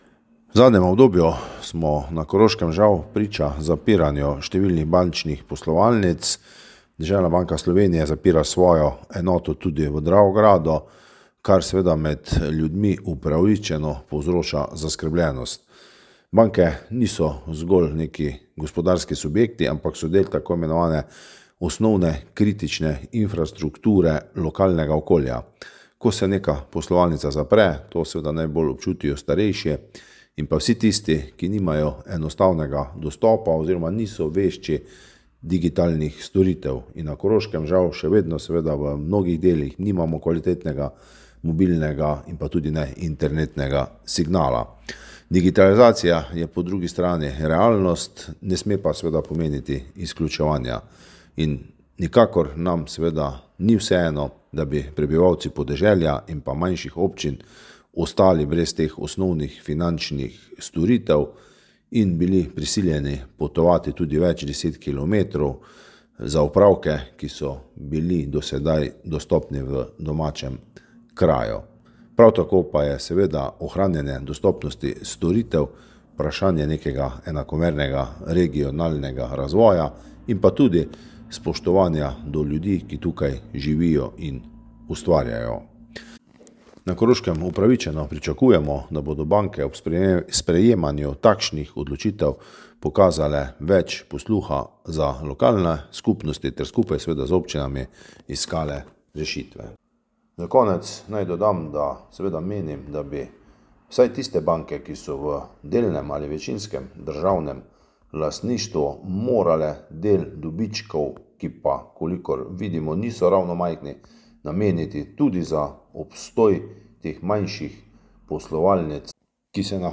Za izjavo smo prosili dravograjskega župana Antona Preksavca
izjava Preksavec_1.mp3